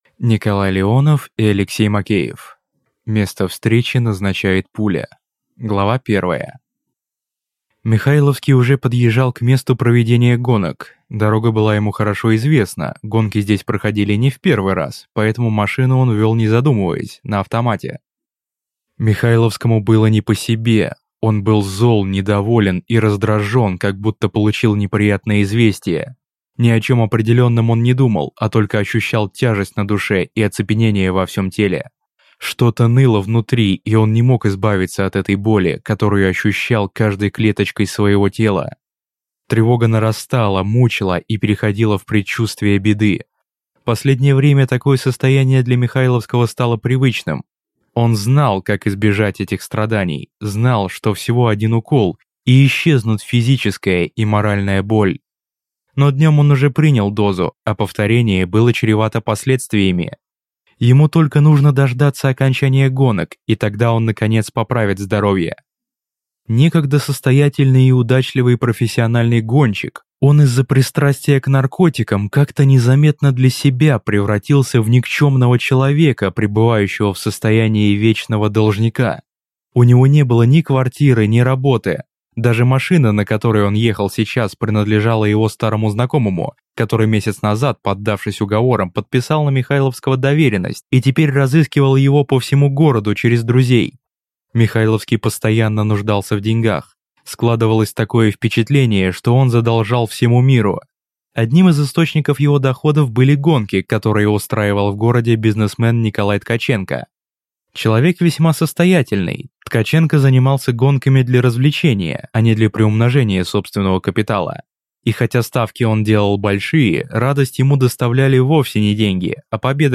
Аудиокнига Место встречи назначает пуля | Библиотека аудиокниг